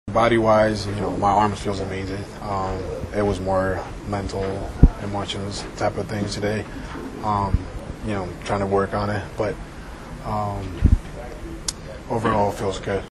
Oviedo said that the problems were more physical than mental.